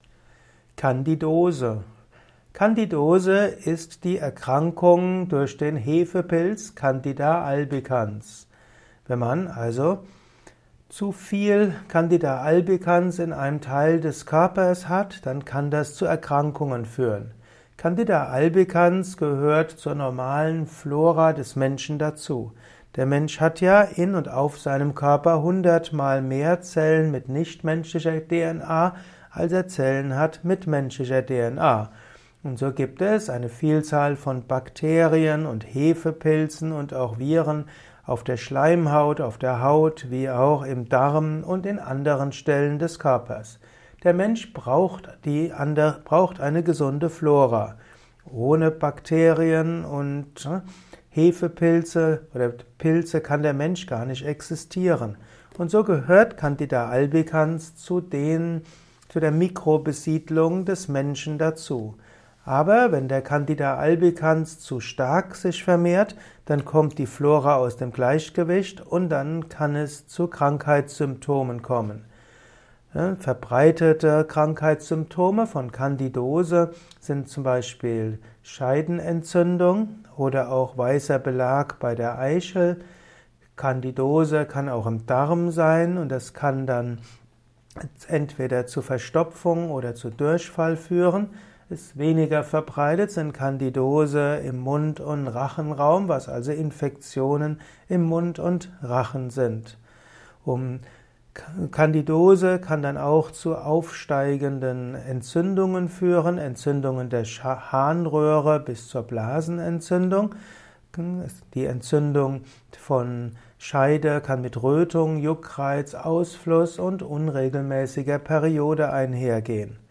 Erfahre mehr über den Begriff Candidose in diesem Kurzvortrag